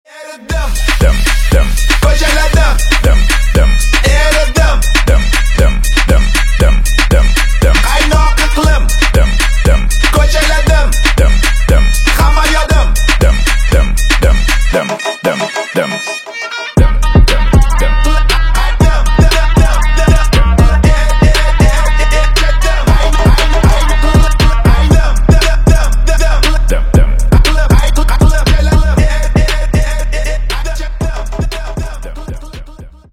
восточные клубные на казахском